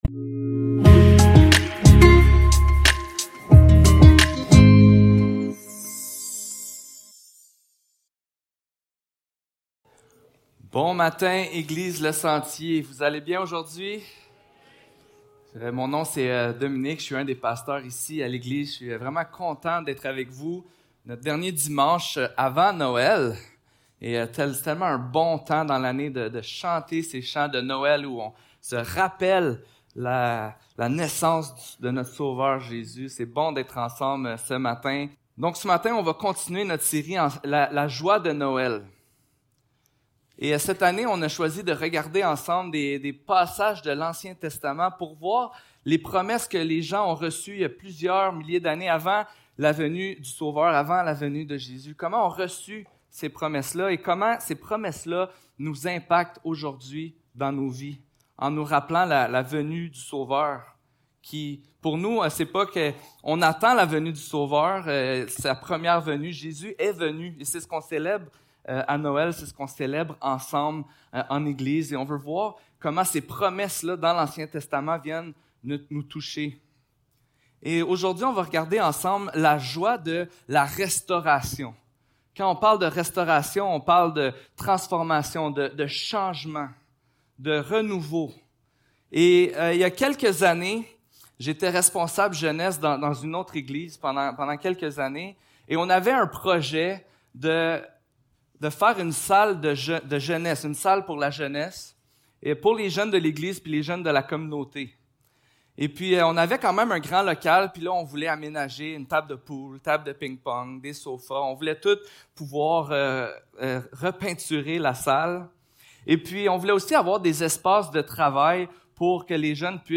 ÉsaÏe 35 Service Type: Célébration dimanche matin Description